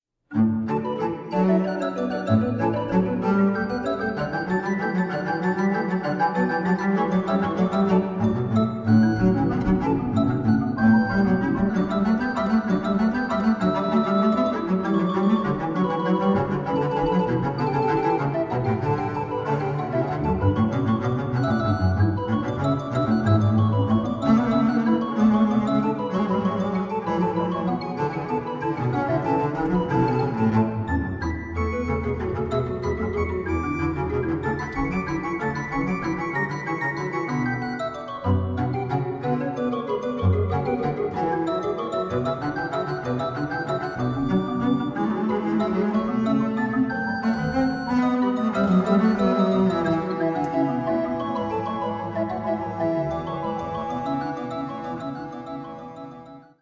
Stereo
positiv organ
(continuo) double bass
harpsichord
violin
viola
cello
double bass